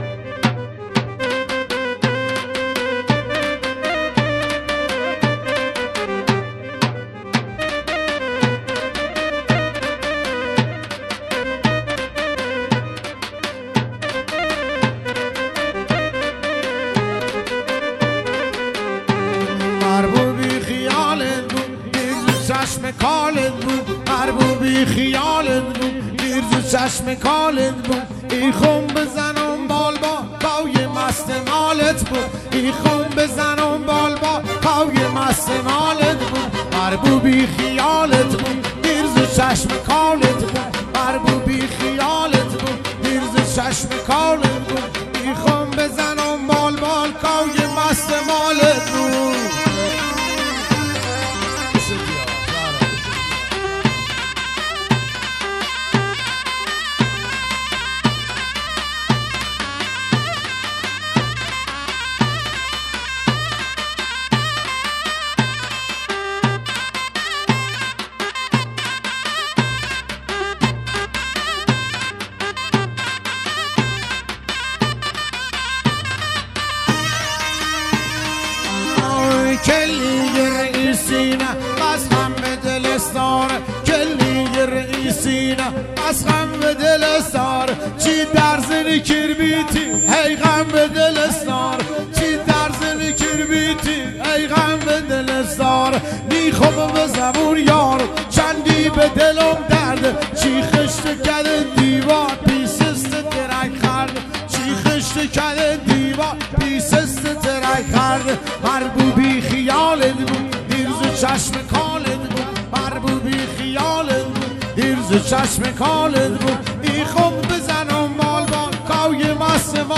محلی لری